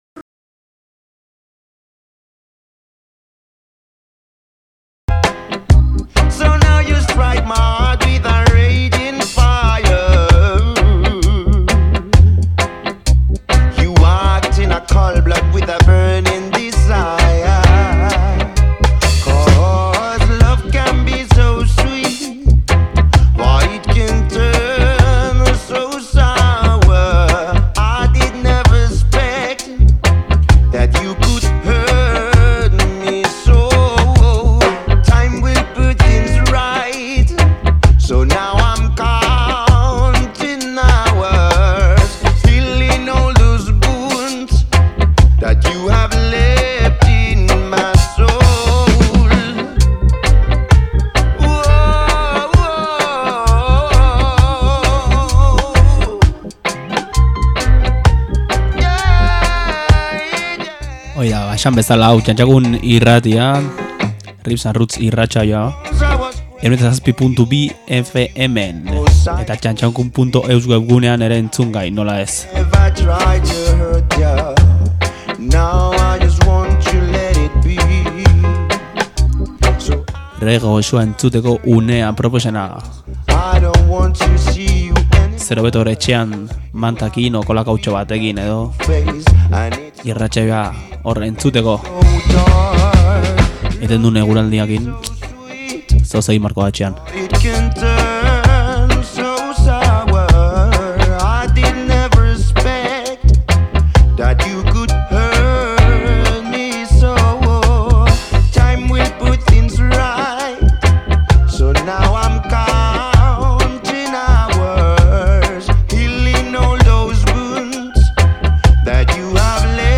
R&R 46: roots binilo saioa